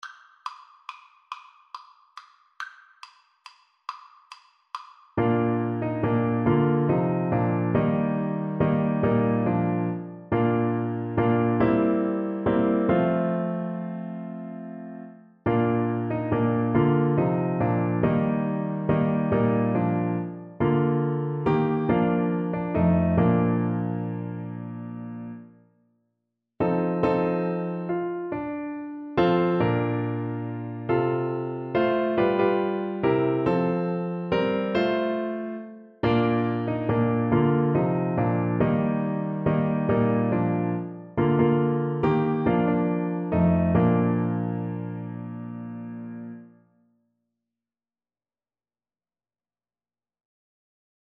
Christian
6/8 (View more 6/8 Music)
Classical (View more Classical Clarinet Music)